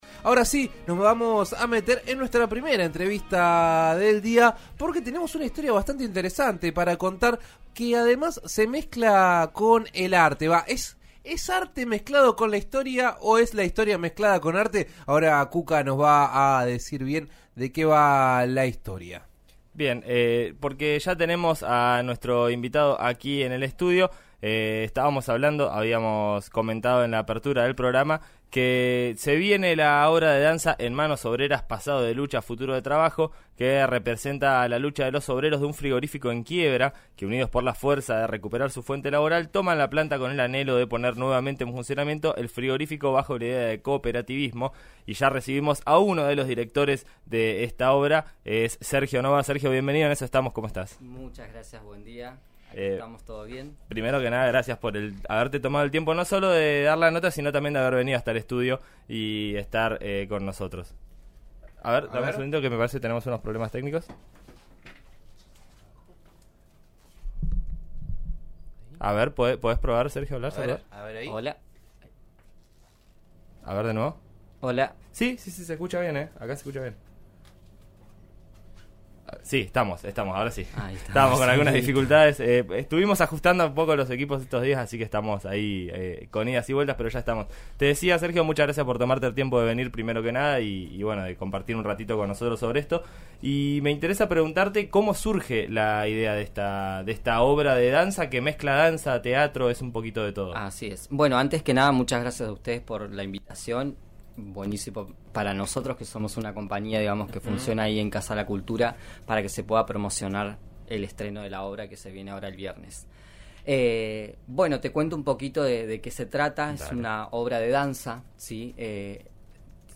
pasó por 'En Eso Estamos' de RN RADIO y comentó de qué trata la obra.